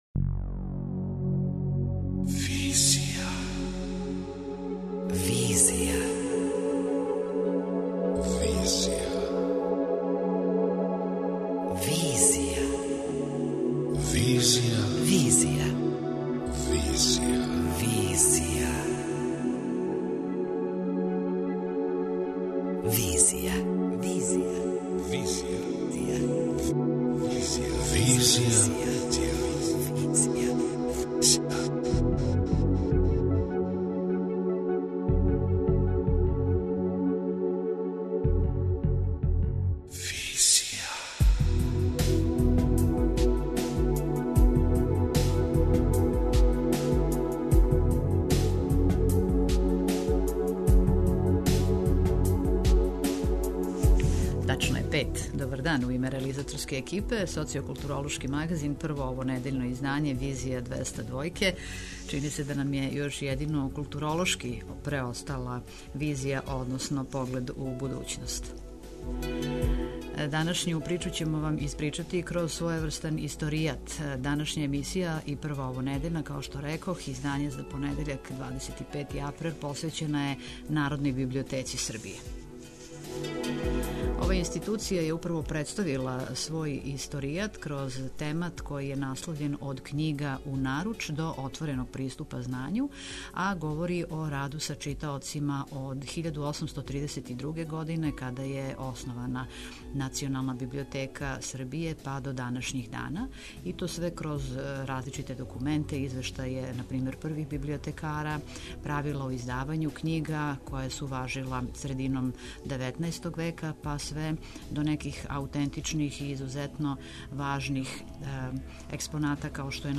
преузми : 26.99 MB Визија Autor: Београд 202 Социо-културолошки магазин, који прати савремене друштвене феномене.